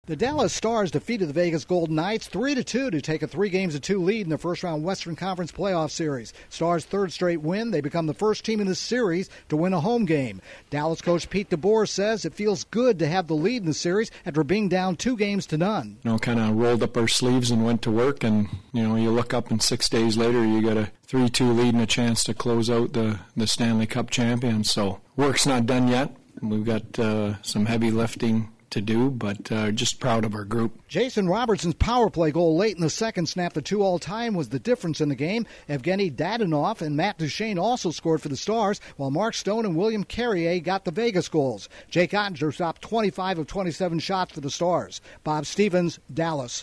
The Dallas Stars are one up over Vegas in Game 5 in their NHL playoff series. Correspondent